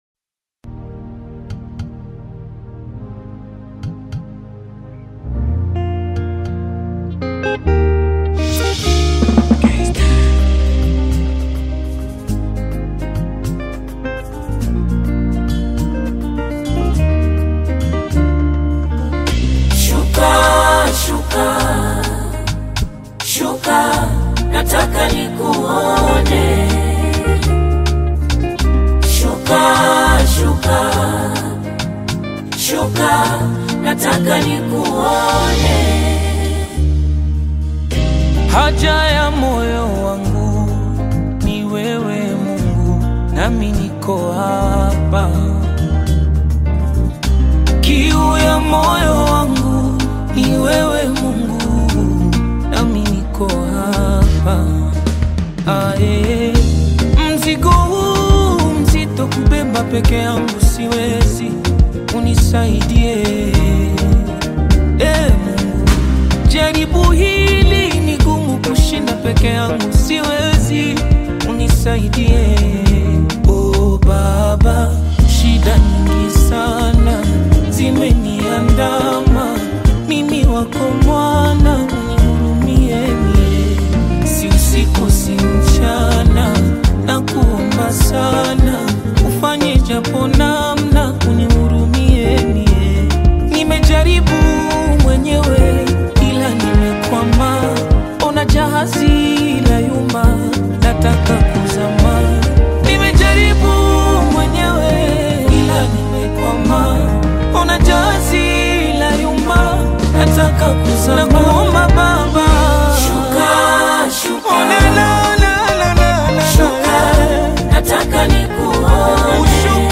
Tanzanian gospel
worship song